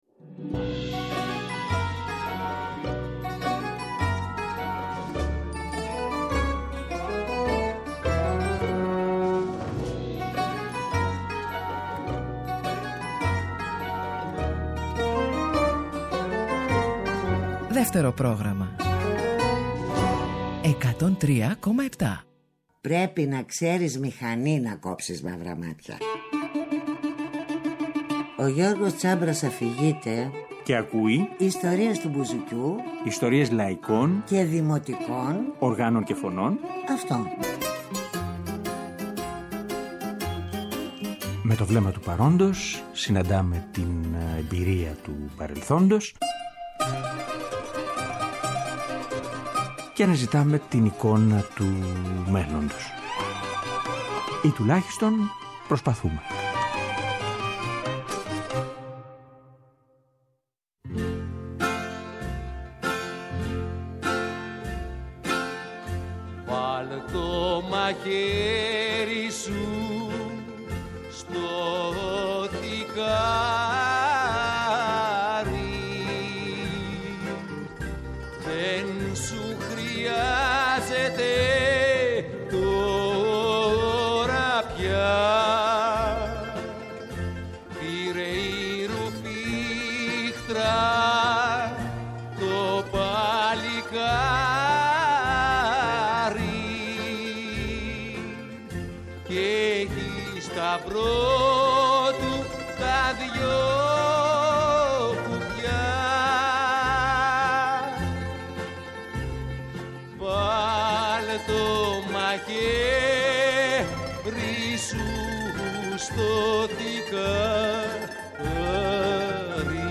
Στην εκπομπή, συνδυάζουμε τραγούδια του τότε και των χρόνων που μεσολάβησαν, με τις σχετικές εμπειρίες και τις σκέψεις του τώρα.